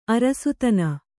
♪ arasutana